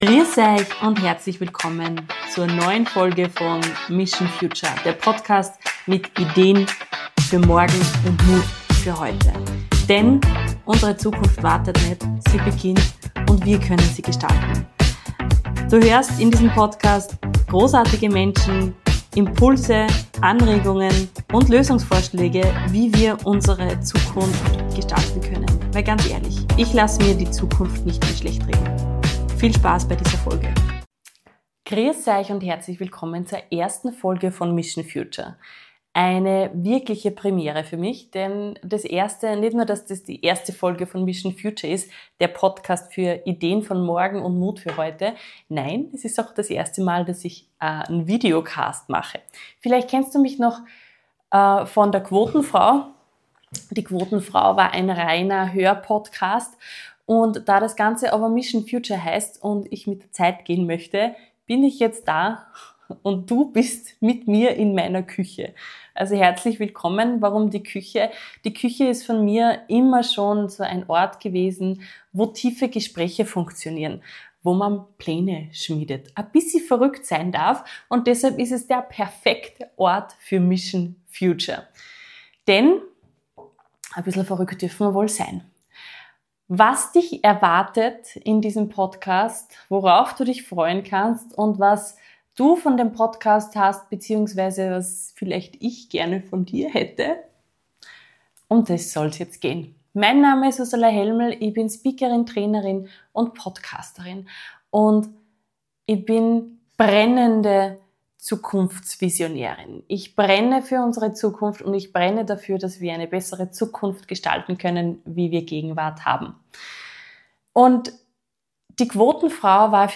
Ich nehme dich mit in meine Küche – den Ort, an dem echte Gespräche und verrückte Ideen entstehen. In dieser Episode erzähle ich, warum ich nicht länger zusehen will, wie unsere Zukunft schlechtgeredet wird, und warum wir alle als Mission Future Crew gebraucht werden. Ich teile meine Vision einer Bewegung für Optimismus und aktiven Wandel, erzähle inspirierende Geschichten, und zeige, dass Lösungen oft dort entstehen, wo wir sie am wenigsten erwarten.